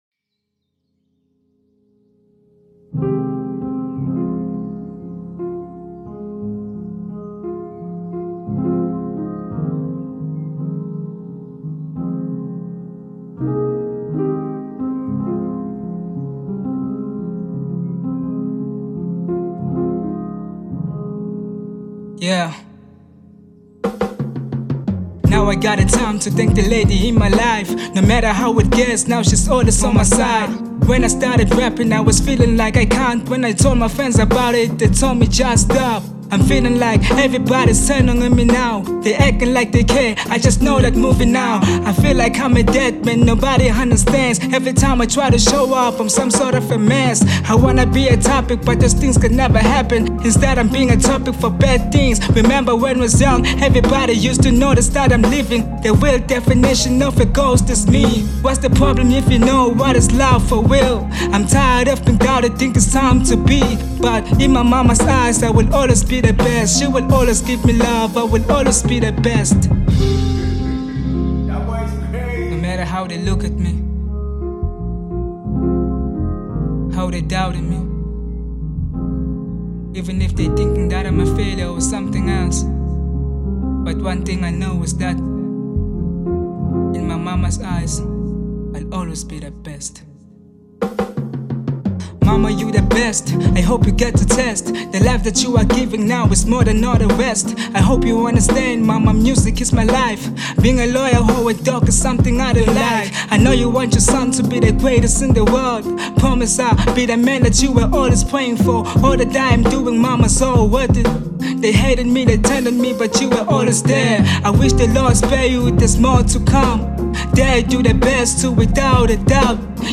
02:42 Genre : Hip Hop Size